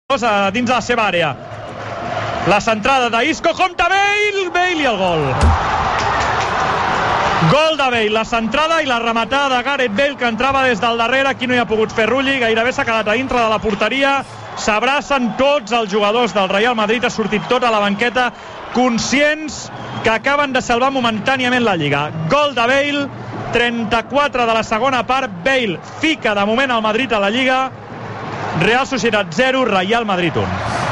Y golpecito a la mesa